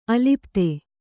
♪ alipte